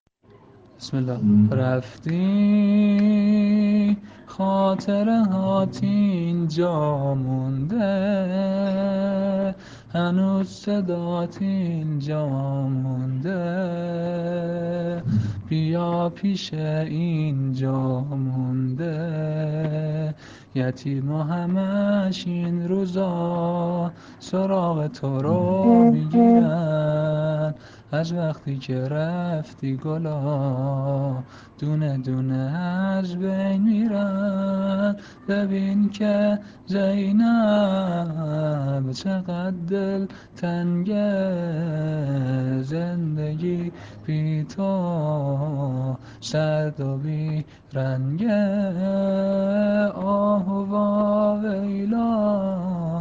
عنوان : سبک زمینه برای شهادت حضرت علی